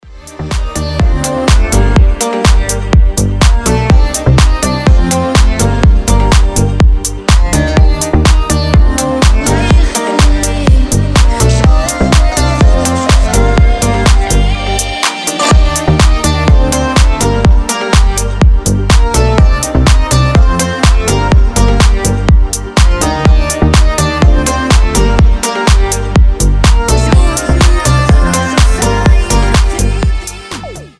гитара
deep house
мелодичные
восточные
индийские
Индийский мотив